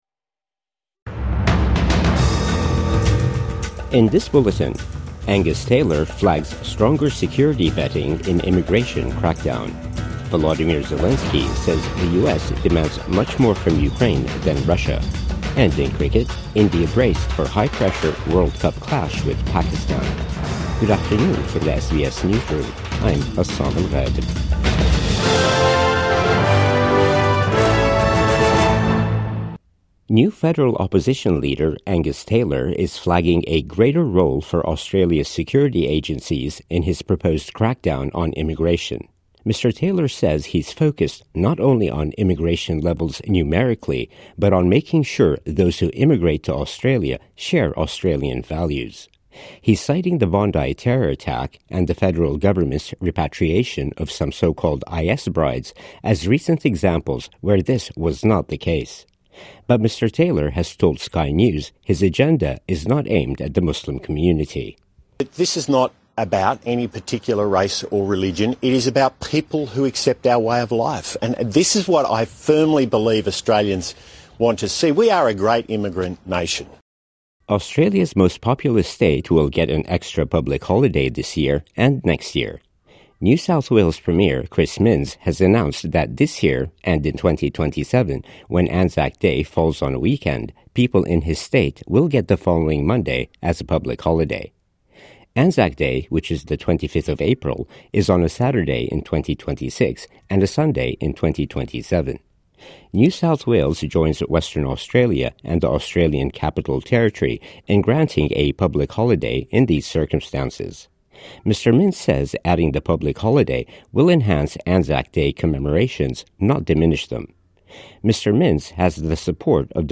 Angus Taylor flags stronger security vetting in immigration crackdown | Midday News Bulletin 15 February 2026